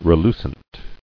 [re·lu·cent]